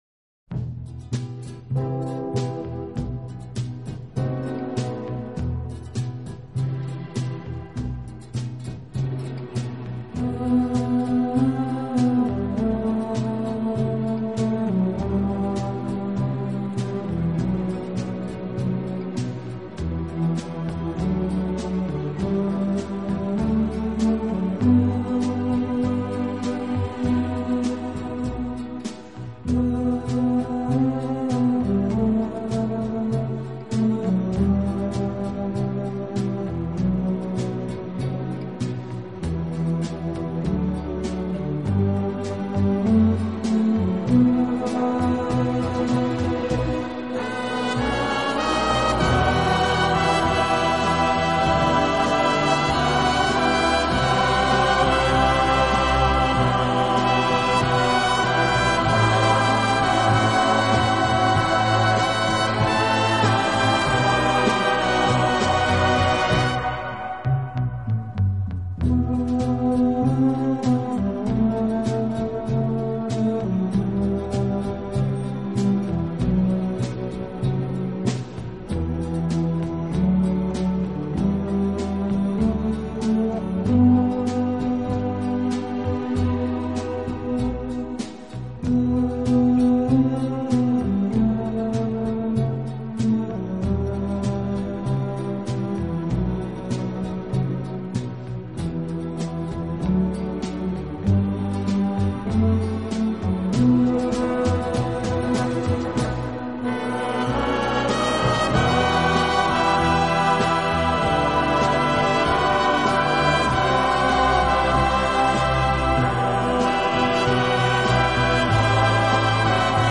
【轻音乐】
小号的音色，让他演奏主旋律，而由弦乐器予以衬托铺垫，音乐风格迷人柔情，声情并
温情、柔软、浪漫是他的特色，也是他与德国众艺术家不同的地方。